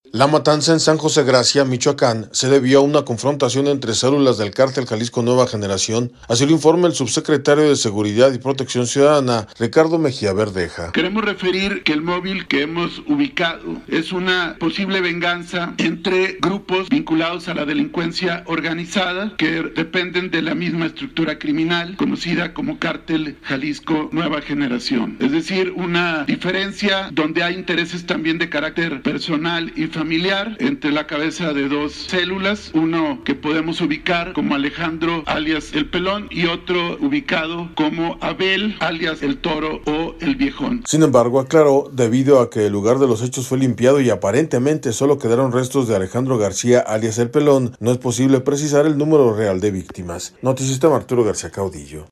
La matanza en San José Gracia, Michoacán, se debió a una confrontación entre celulas del Cártel Jalisco Nueva Generación, así lo informa el subsecretario de Seguridad y Protección Ciudadana, Ricardo Mejía Berdeja.